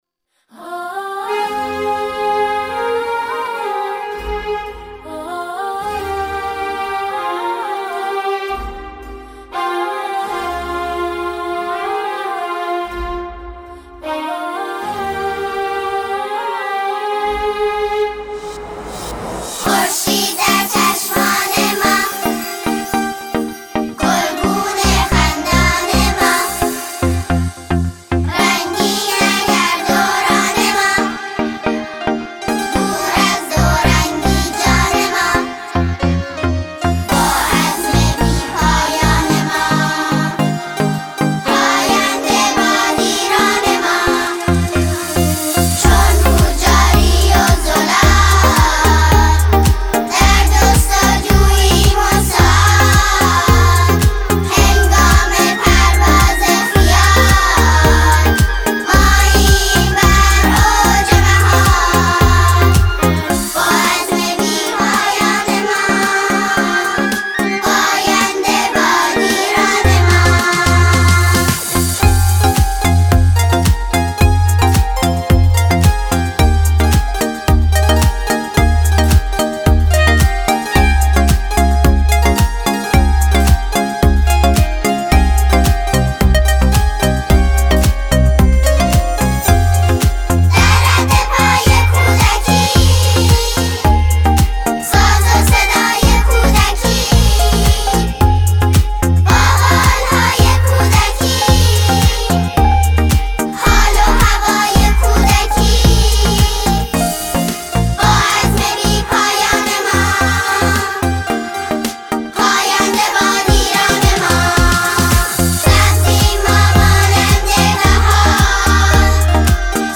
گروه سرود